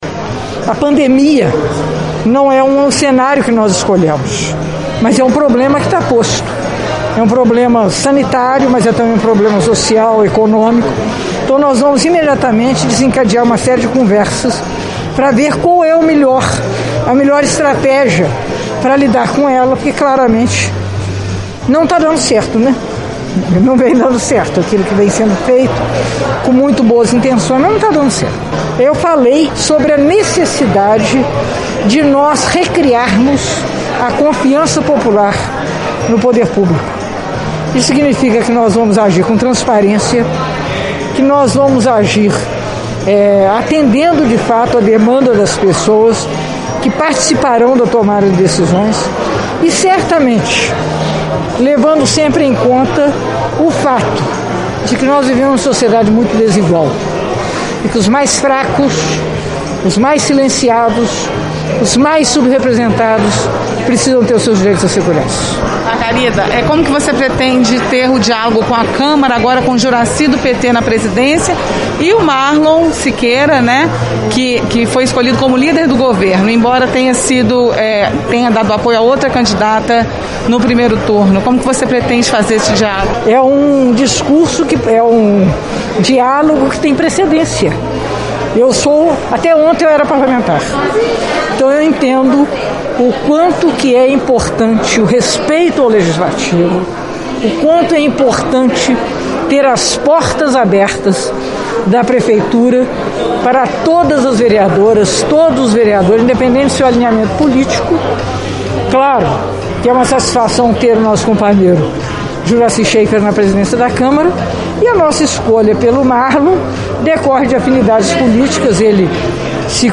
Em solenidade realizada na Câmara Municipal, na noite desta sexta-feira, 1º de janeiro de 2021, Margarida Salomão falou sobre uma gestão participativa; a construção de um estado de direito a partir da base vinda dos bairros para o centro; e atenção para as minorias. Destacou também o diálogo entre as forças políticas e o principal desafio nesse primeiro momento.
02_Posse_Margarida-Salomao.mp3